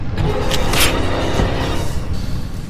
Among Us Death 4 Sound Effect Free Download